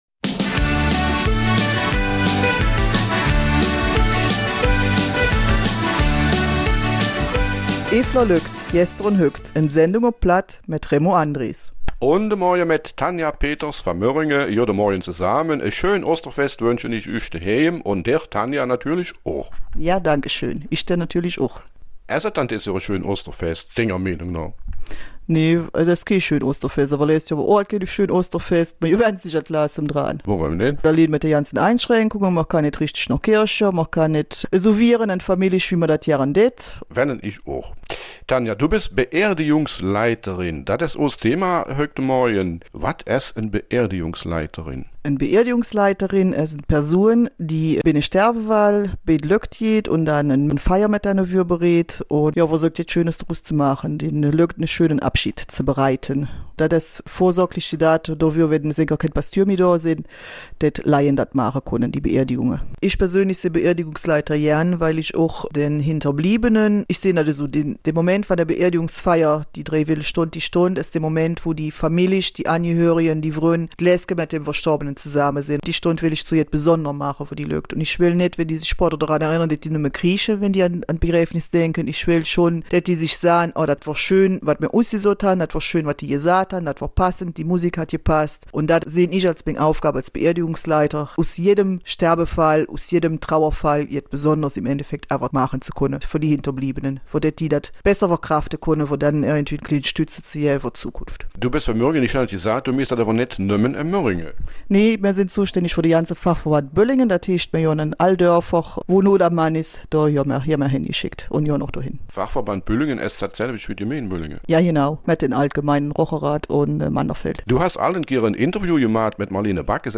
Eifeler Mundart: Nach dem Tode die Auferstehung